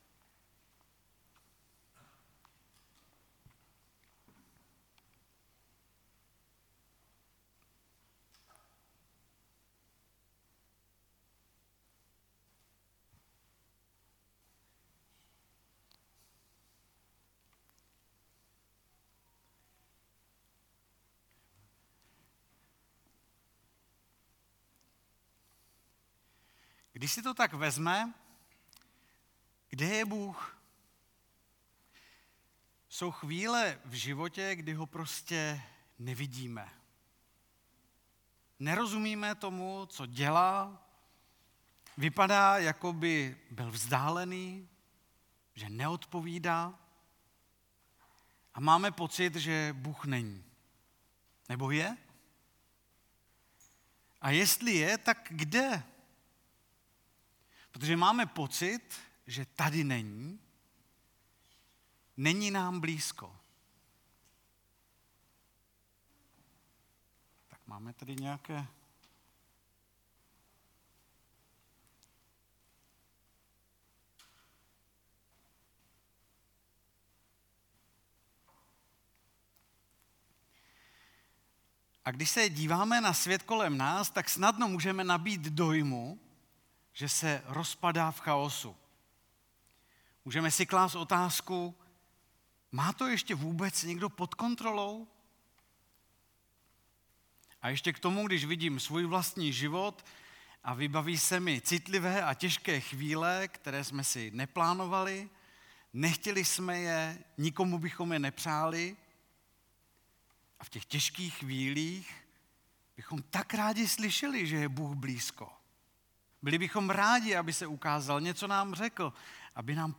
Vánoční bohoslužba